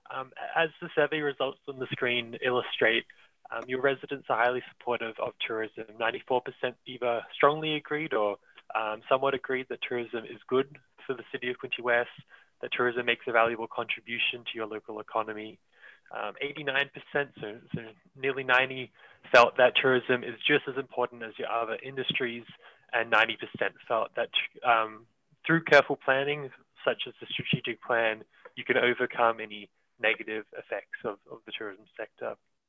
At Monday’s Quinte West council meeting, council heard an update on the tourism development strategy for the municipality.